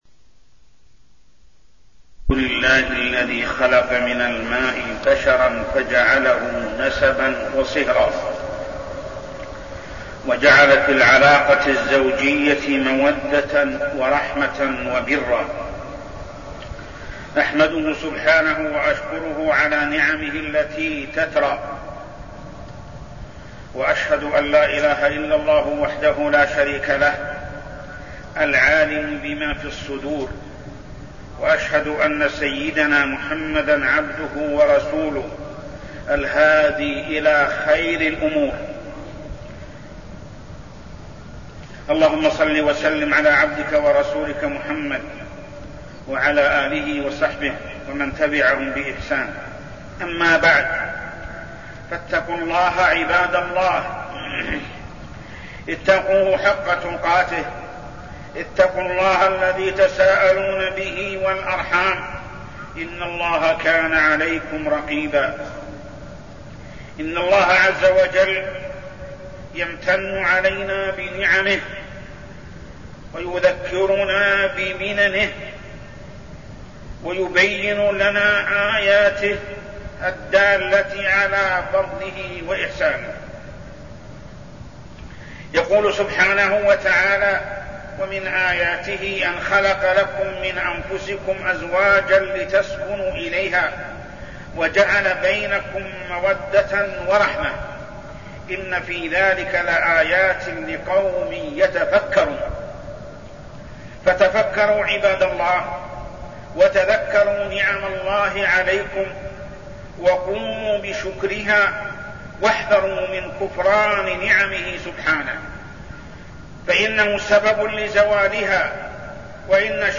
تاريخ النشر ٢٤ شعبان ١٤١٤ هـ المكان: المسجد الحرام الشيخ: محمد بن عبد الله السبيل محمد بن عبد الله السبيل الحياة الزوجية